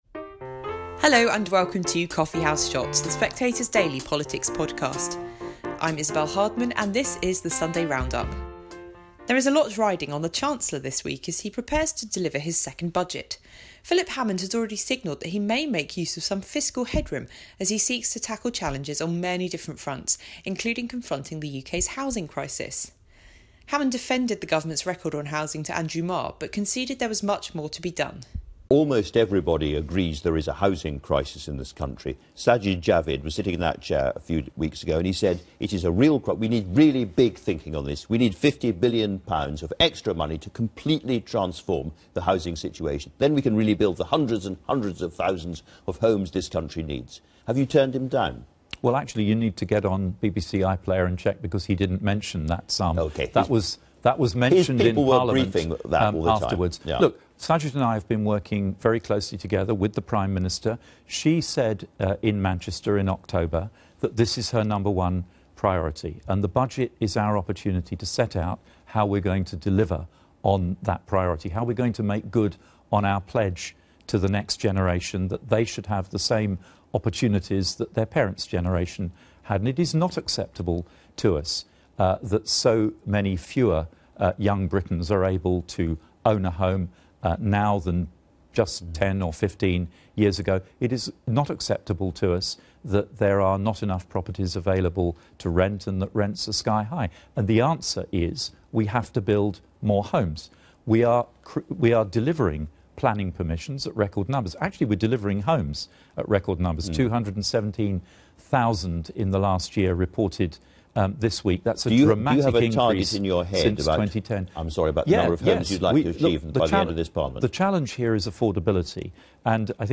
Isabel Hardman welcomes you to the best summary of Sunday's political interviews on offer. This week's episode features Philip Hammond, John McDonnell, Andy Burnham and Jacob Rees-Mogg.